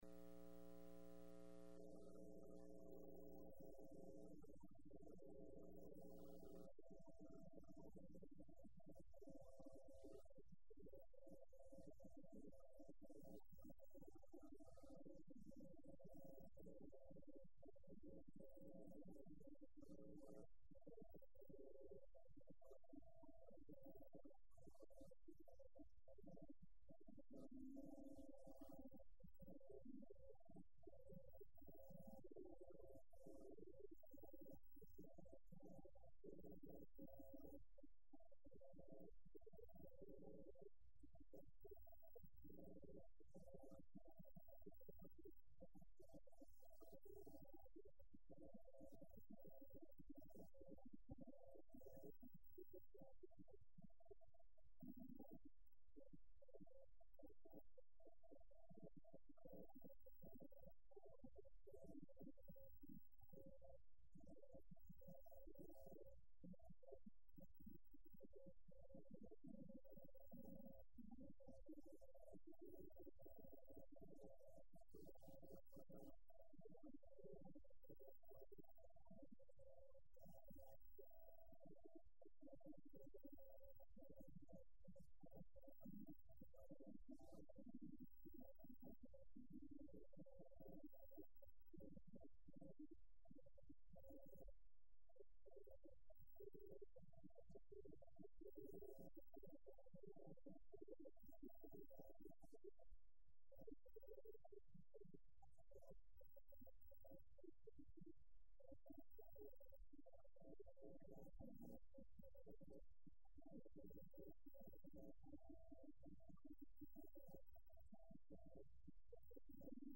10/26/11 Wednesday Evening